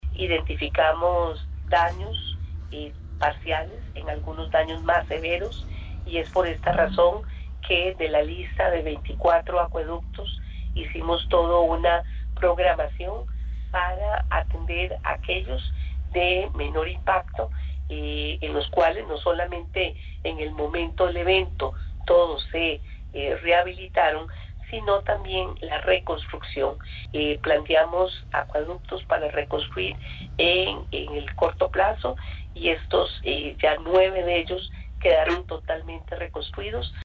La presidente del AyA, Yamileth Astorga, explicó el plan e intervención que realizó la institución, una vez que la emergencia nacional terminó.